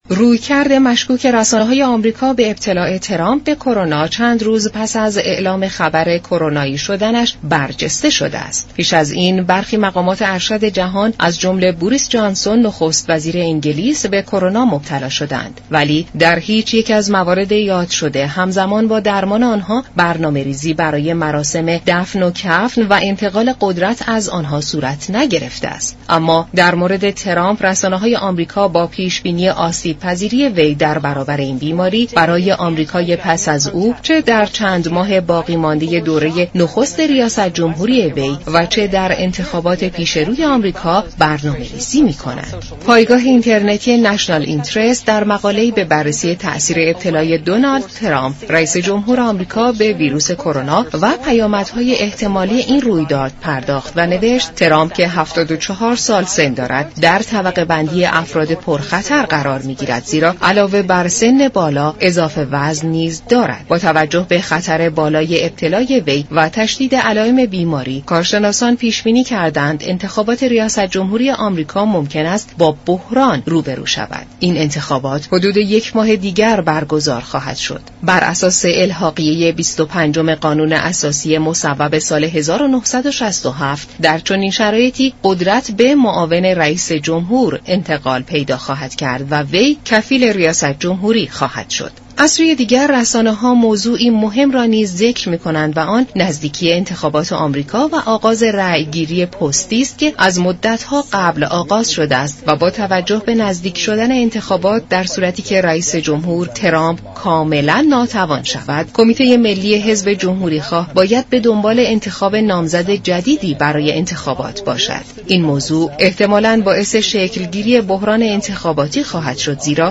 برنامه جهان سیاست شنبه تا چهارشنبه هر هفته ساعت 15:30 از رادیو ایران پخش می شود.